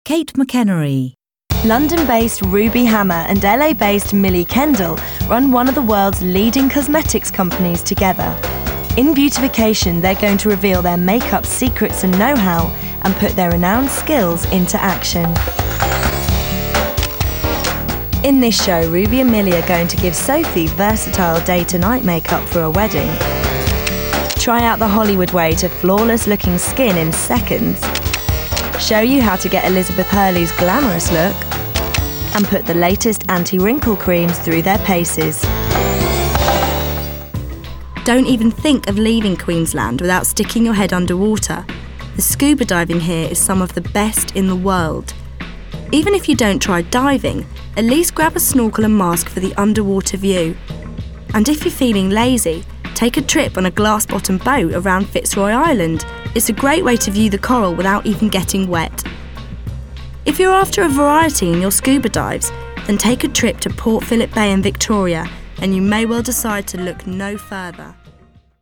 Description: Neutral: sassy, versatile, confident
Age range: 30s - 40s
Commercial 0:00 / 0:00